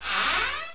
wooddoor.wav